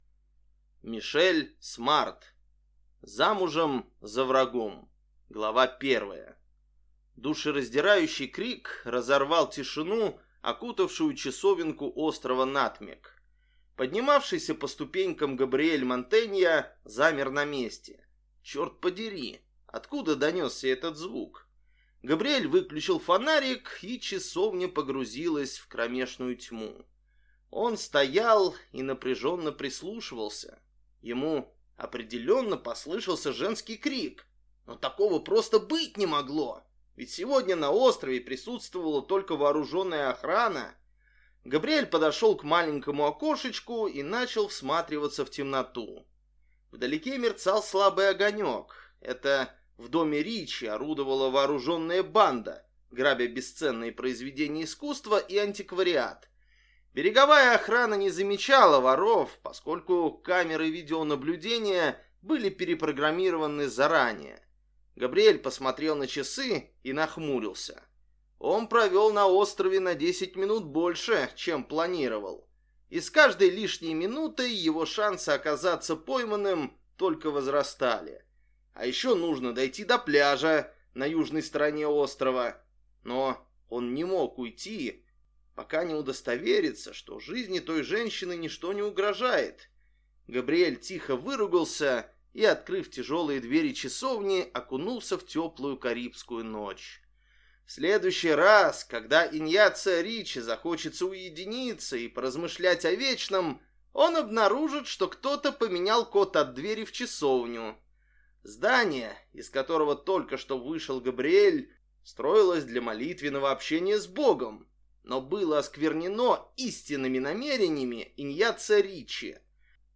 Аудиокнига Замужем за врагом | Библиотека аудиокниг
Прослушать и бесплатно скачать фрагмент аудиокниги